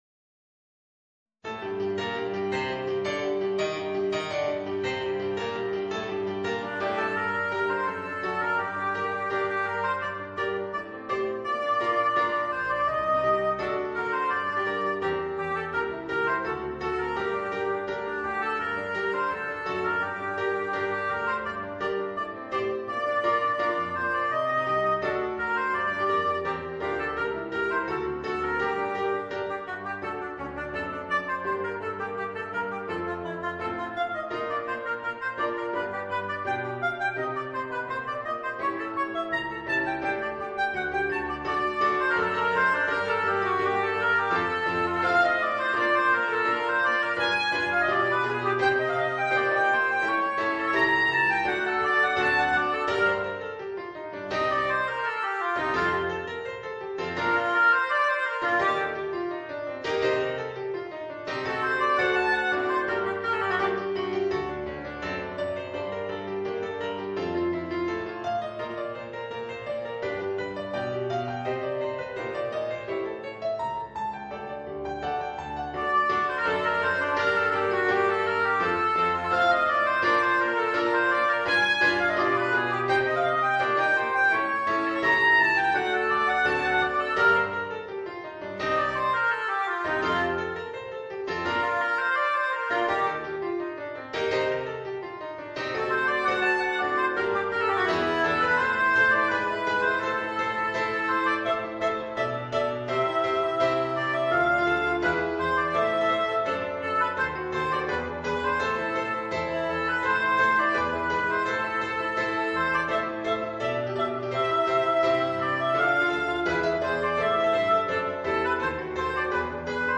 Voicing: Oboe and Piano